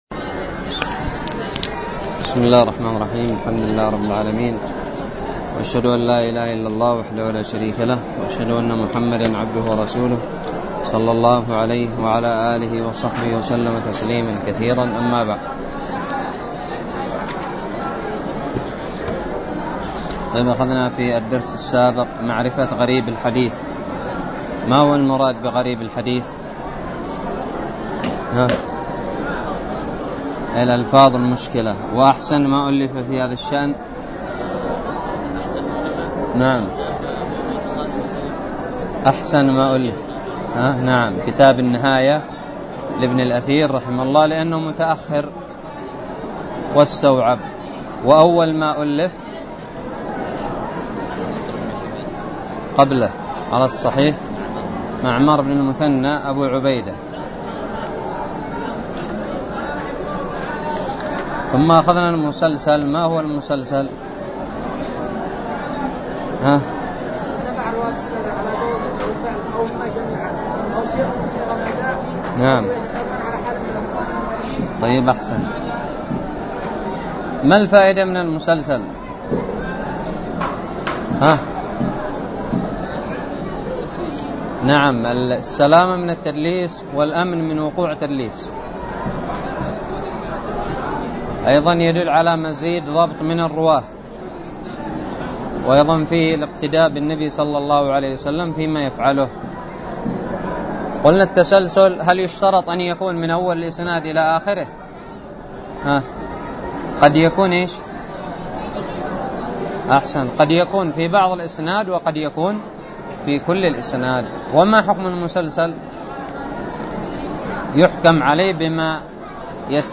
الدرس الرابع والأربعون من شرح كتاب الباعث الحثيث
ألقيت بدار الحديث السلفية للعلوم الشرعية بالضالع